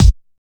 kick03.wav